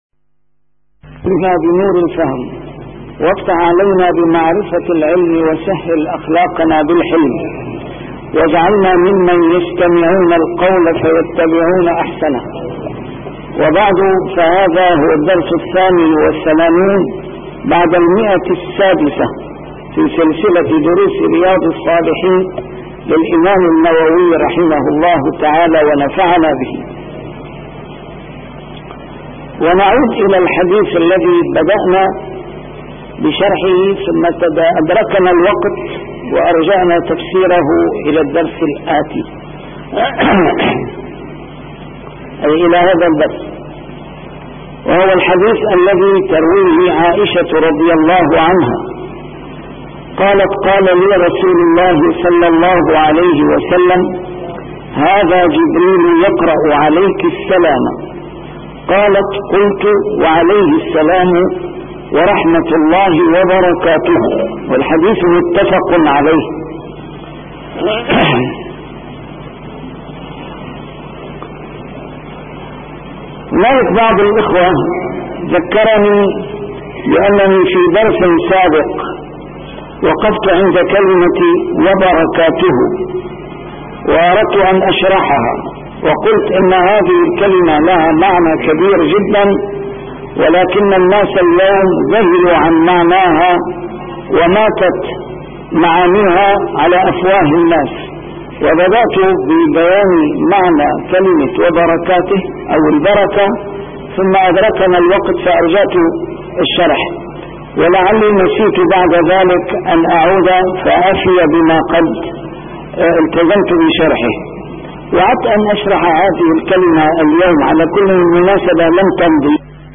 A MARTYR SCHOLAR: IMAM MUHAMMAD SAEED RAMADAN AL-BOUTI - الدروس العلمية - شرح كتاب رياض الصالحين - 688-شرح رياض الصالحين: كيفية السلام